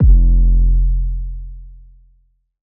SOUTHSIDE_808_nastytalk_F.wav